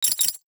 NOTIFICATION_Metal_12_mono.wav